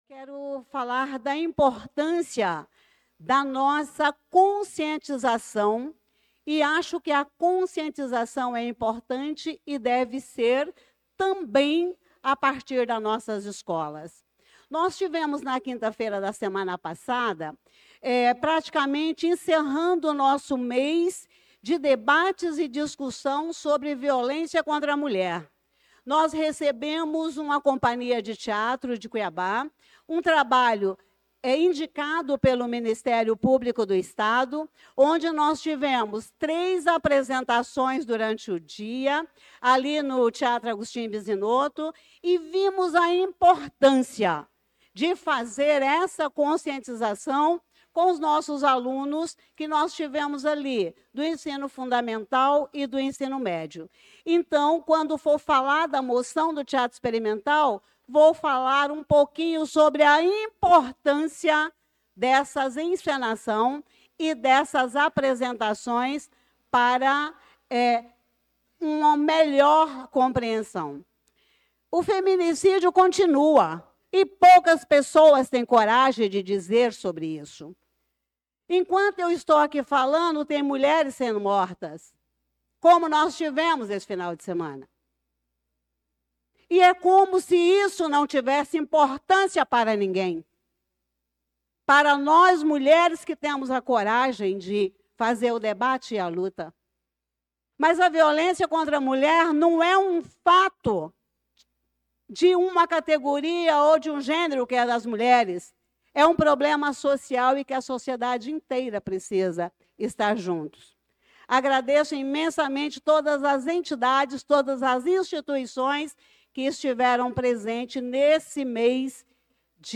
Pronunciamento da vereadora Elisa Gomes na Sessão Ordinária do dia 25/08/2025.